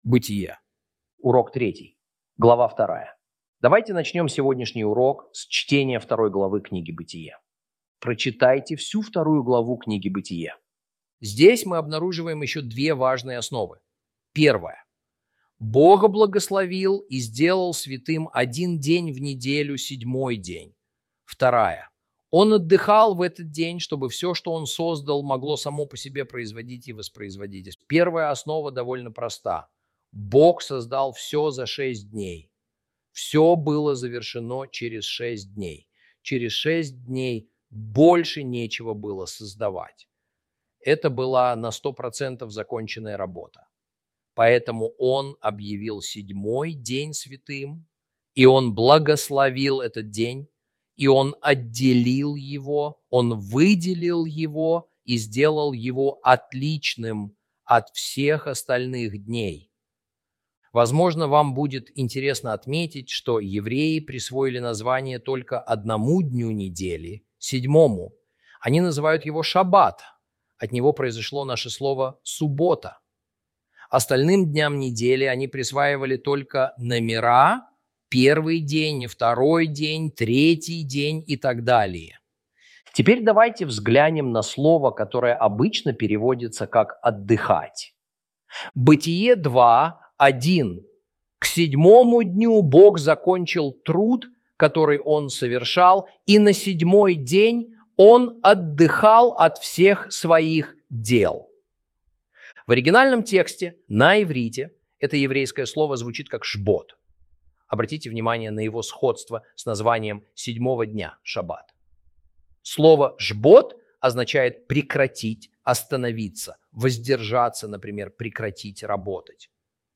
Урок 3 - Бытие 2 - Torah Class